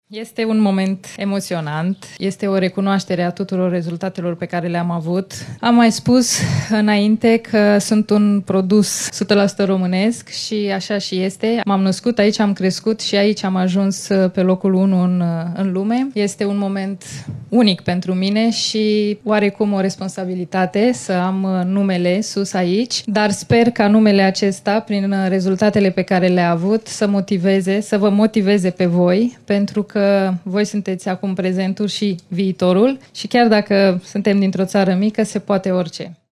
Sunt un produs sută la sută românesc” a declarat și Simona Halep, emoționată, la inaugurare.